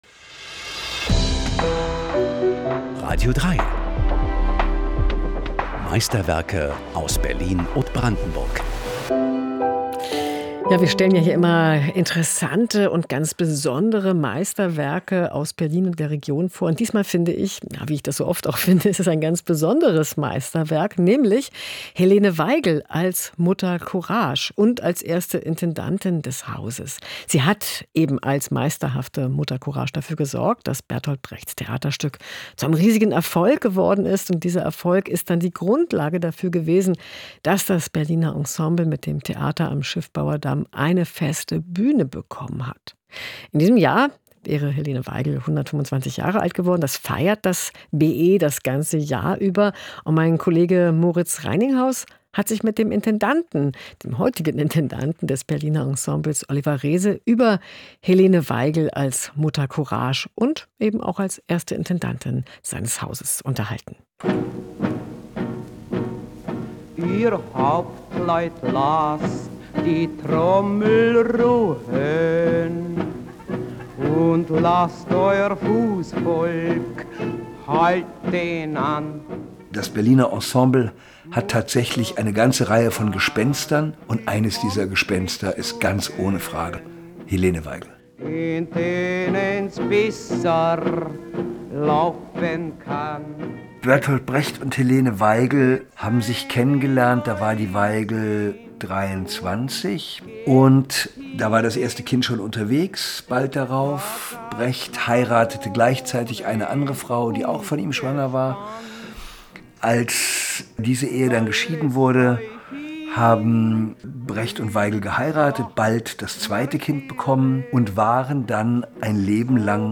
Eine Ton-Collage